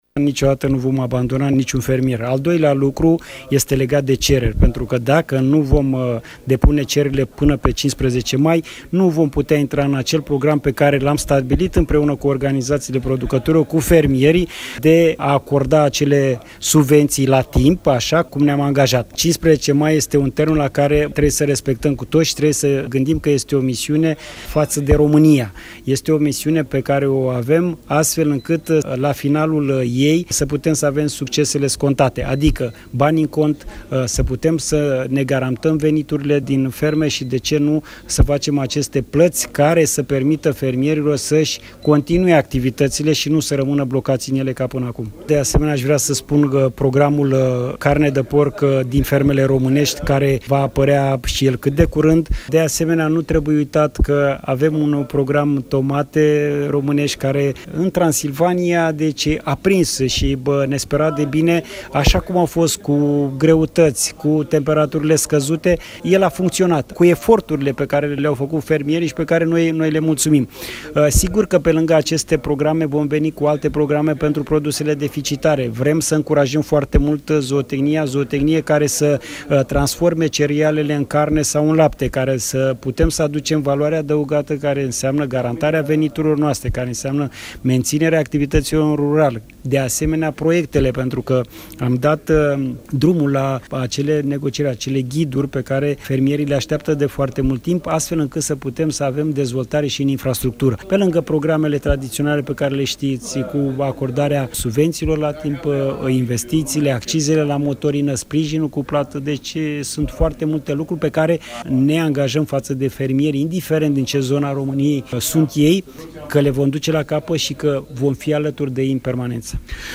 12-mai-secretar-de-stat-agricultura-Botanoiu.mp3